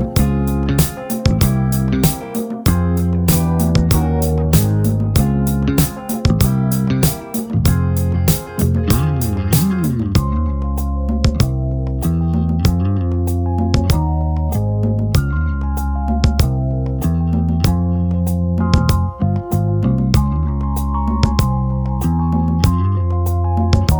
no Backing Vocals Soul / Motown 3:54 Buy £1.50